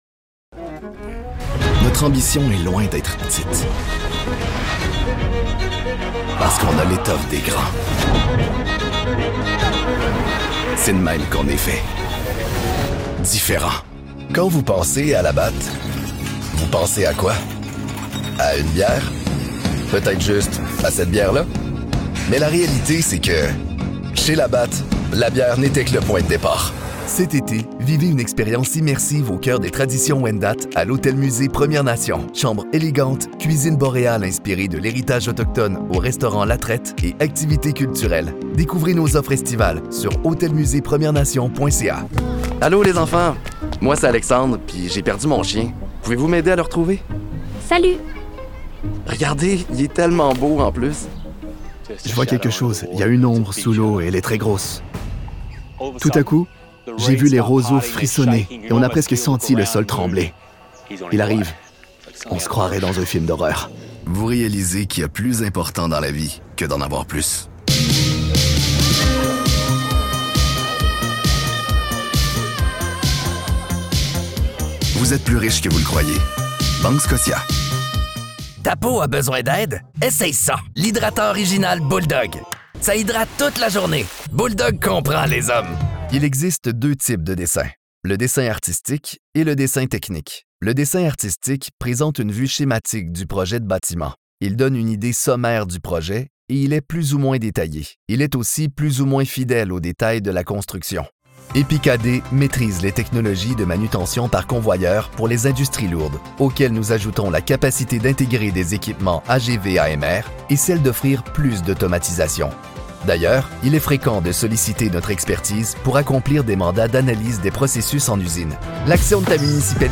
Never any Artificial Voices used, unlike other sites.
Foreign & British Male Voice Over Artists & Actors
Adult (30-50) | Yng Adult (18-29)